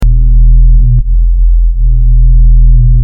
Ambient Hum
Ambient_hum.mp3